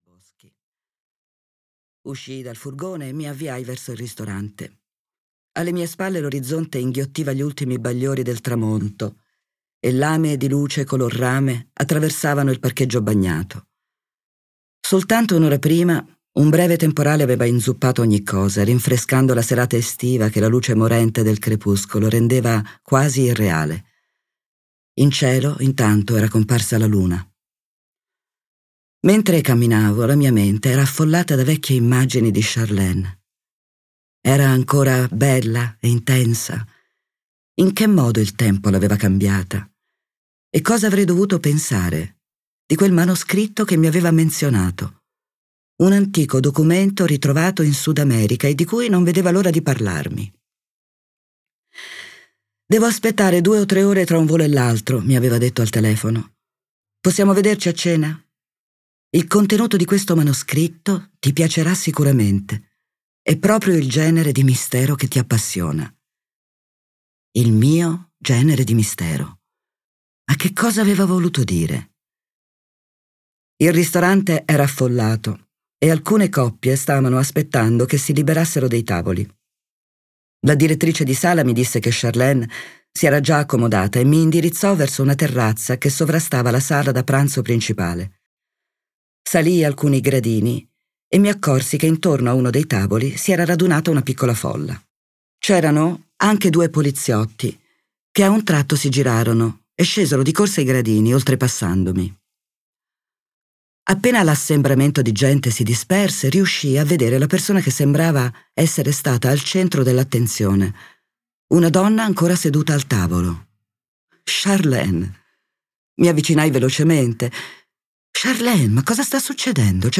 "La Profezia di Celestino" di James Redfield - Audiolibro digitale - AUDIOLIBRI LIQUIDI - Il Libraio
• Letto da: Monica Guerritore